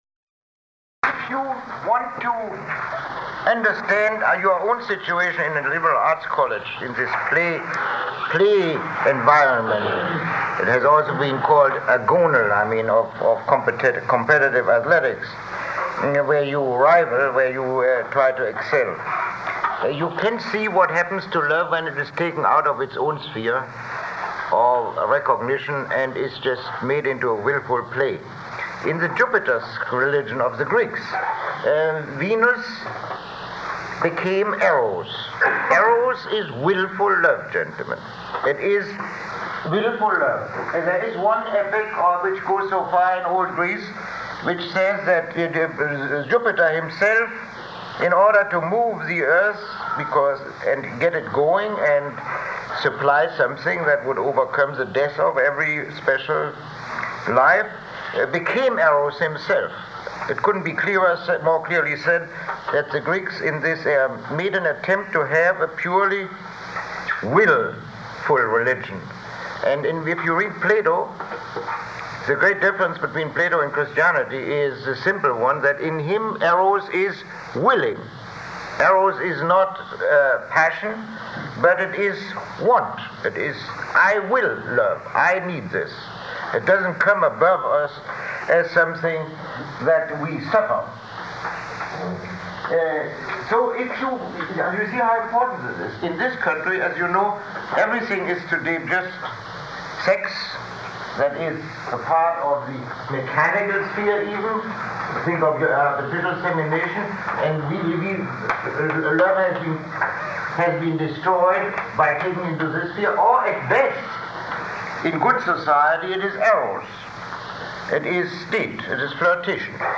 Lecture 19